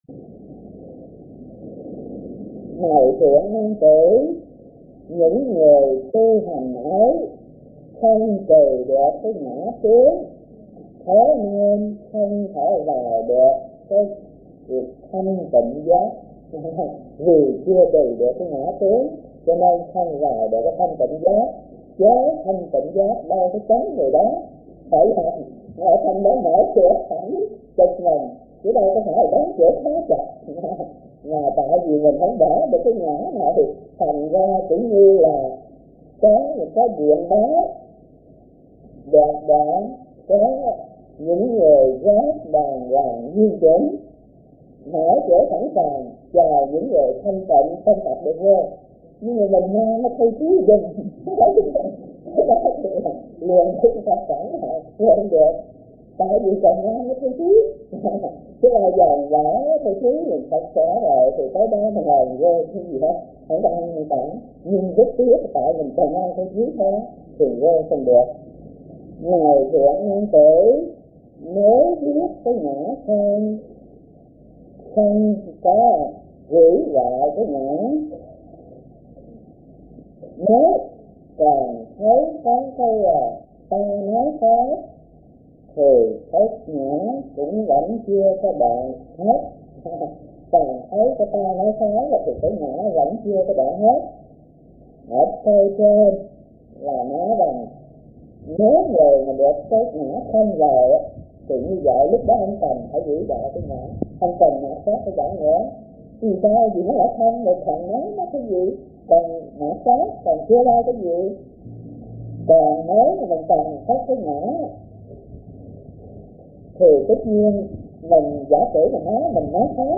Kinh Giảng Kinh Viên Giác - Thích Thanh Từ